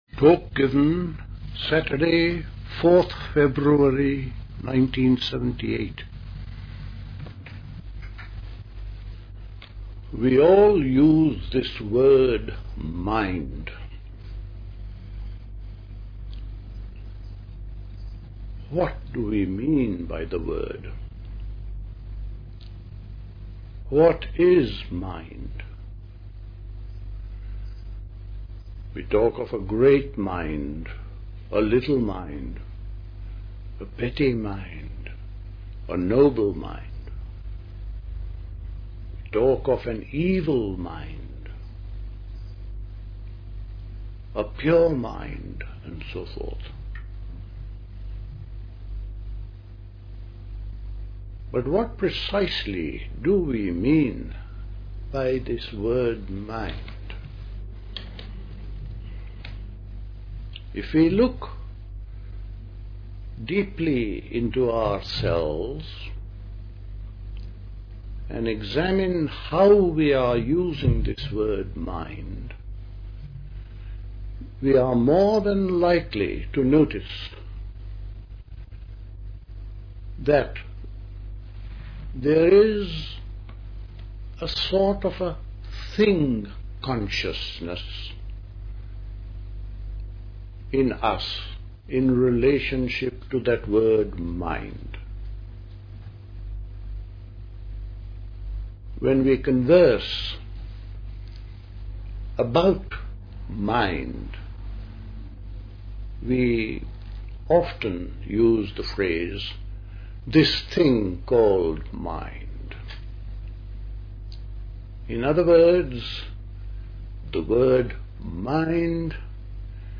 Dilkusha, Forest Hill, London